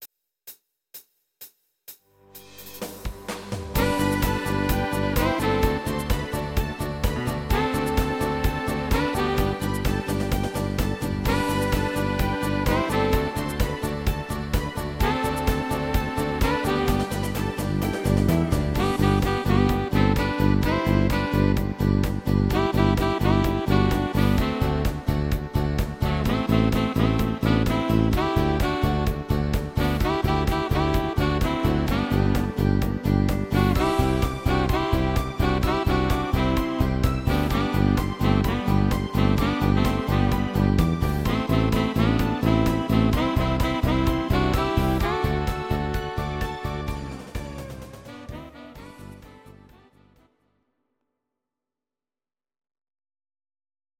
Audio Recordings based on Midi-files
Our Suggestions, Pop, German, 2020s